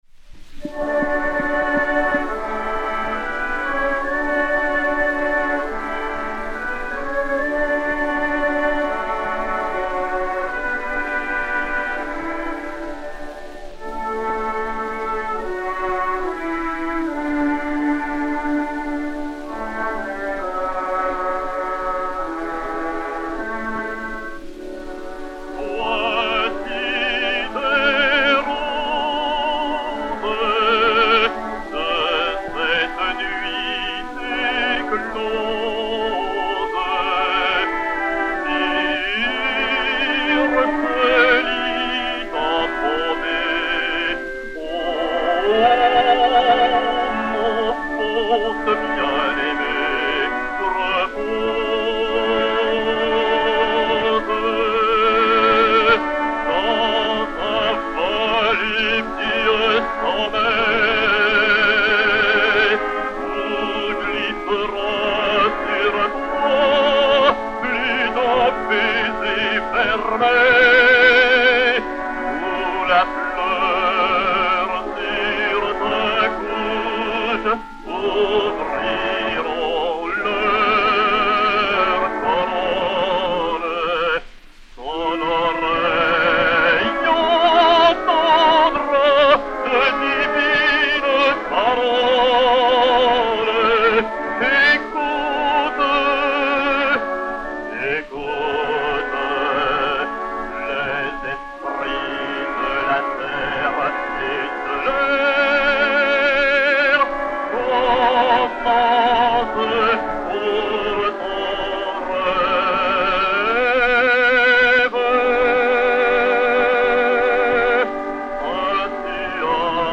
basse française
et Orchestre